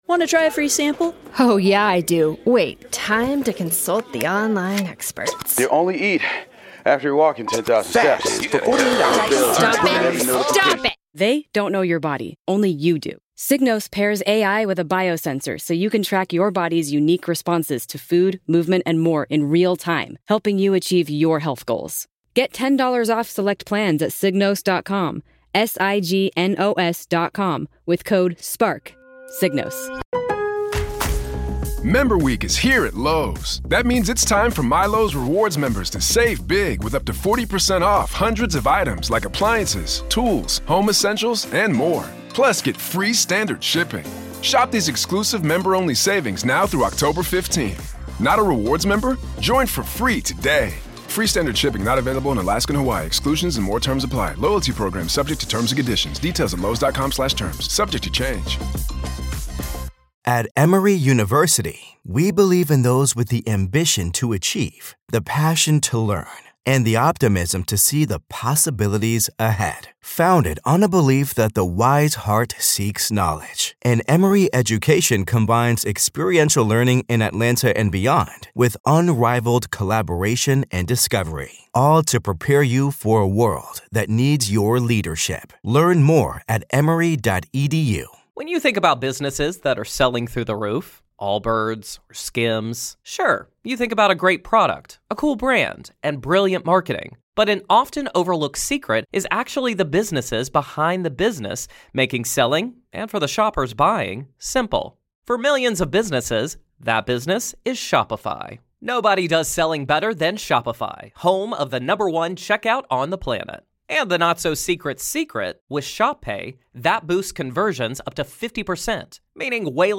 This week on the Chunkz Show a group of random individuals put forward their interesting statements and have until I press the buzzer to argue their case....Find me each week on Youtube, Spotify, Apple podcasts, or wherever you get your podcasts!